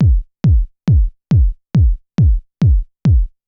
BD        -L.wav